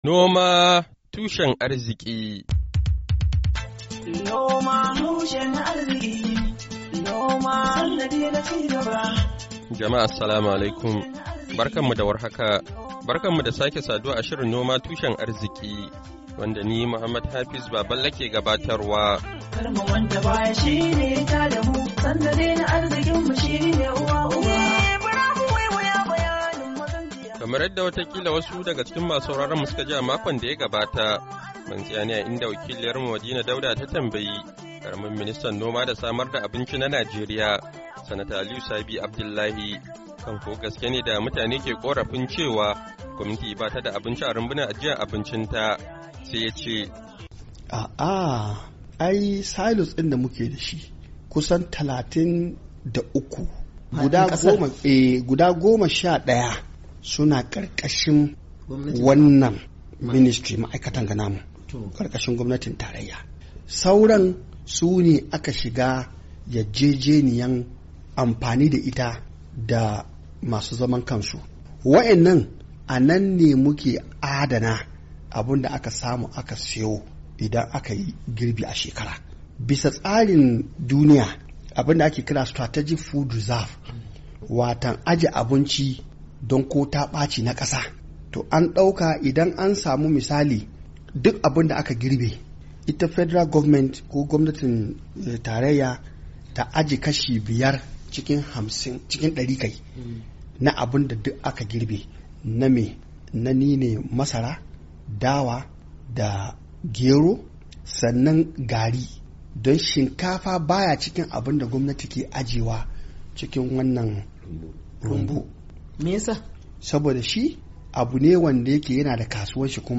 Shirin Noma Tushen Arzki na wannan makon, zai kawo mu ku ci gaban tattauna wa da karamin ministan noma da samar da abinci na Najeriya, Sanata Aliyu Sabi Abdullahi kan batun irin matakan da gwamnatin Najeriya take dauka don inganta samar da abinci a fadin kasar, bayan da al'umma su ka gudanar da zanga-zanga kan tsananin tsadar rayuwa a farkon watan Agusta.